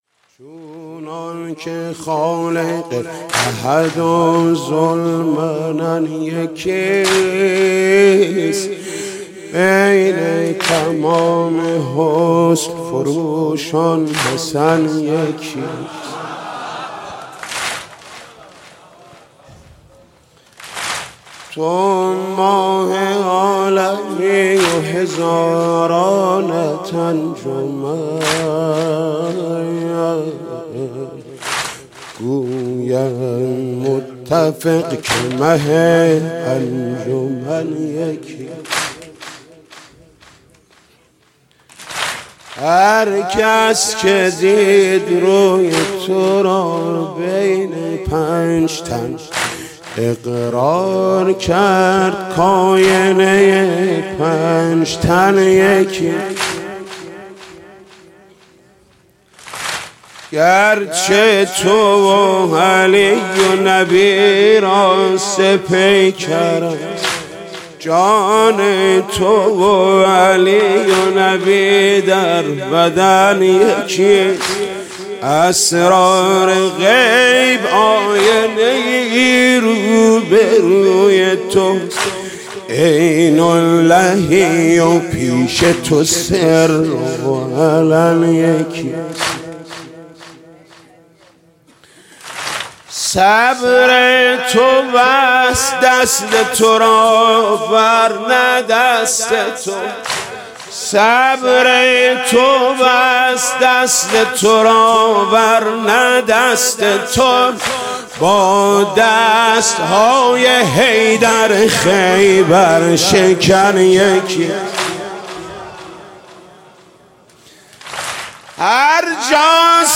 ویژه شهادت امام حسن (واحد)